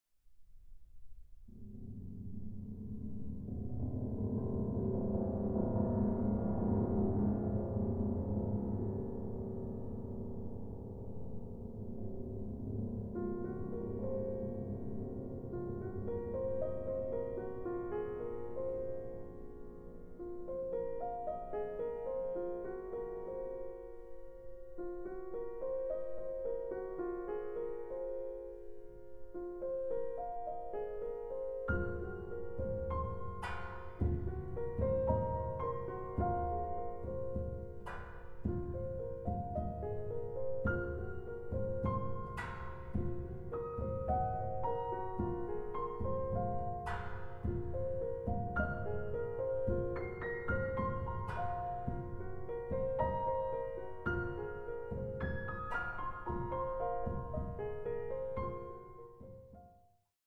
Recording: Mendelssohn-Saal, Gewandhaus Leipzig, 2024